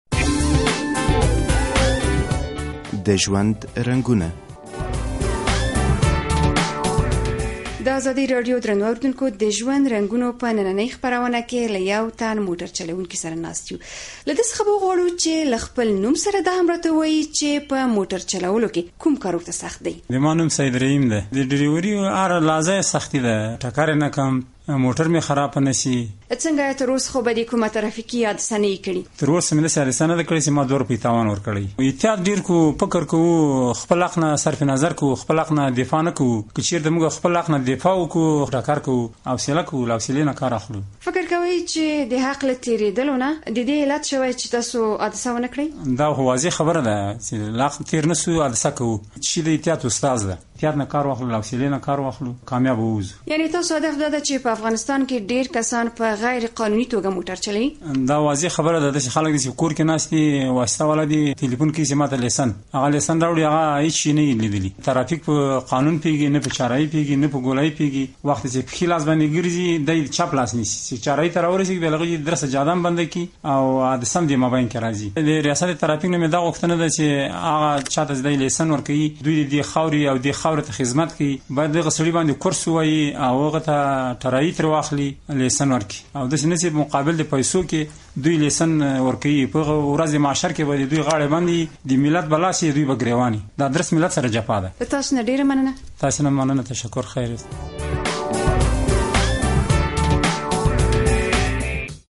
له یوه موټر چلونکي سره مو دا ځل مرکه کړې نوموړی وايي زه پخپله ډیر احتیاط کوم.